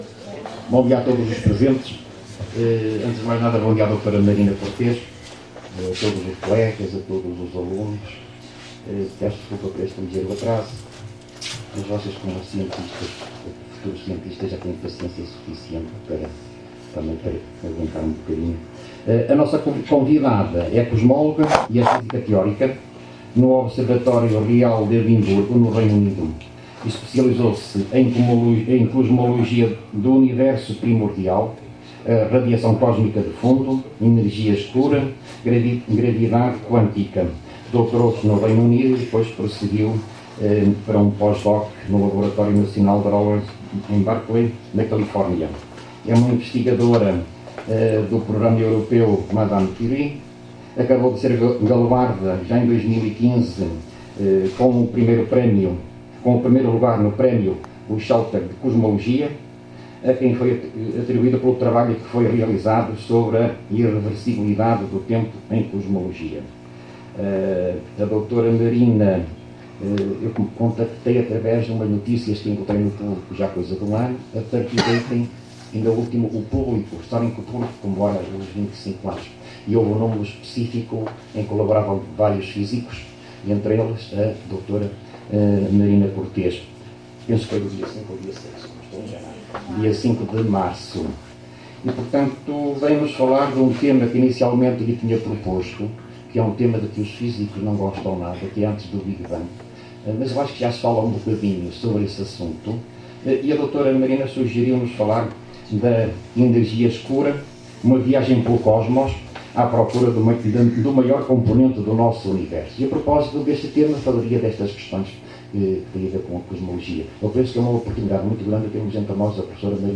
Conferência